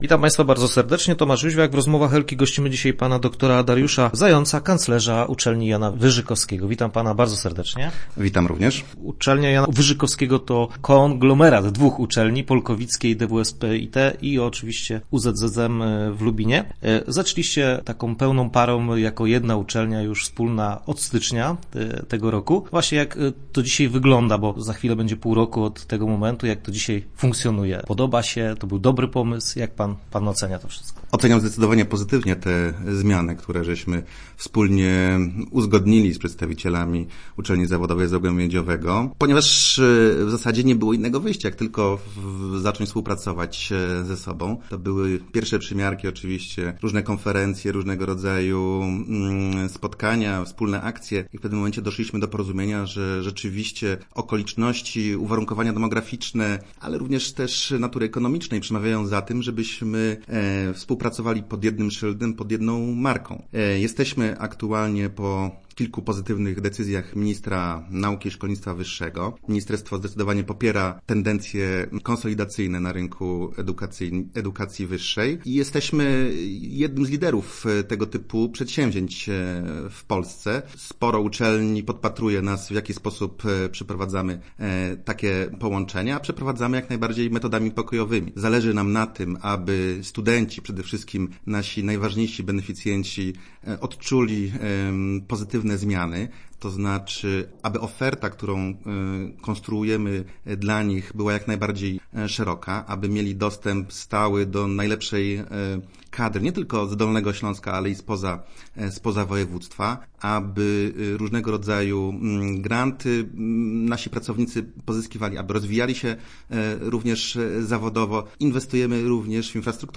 Start arrow Rozmowy Elki arrow Nie było innego wyjścia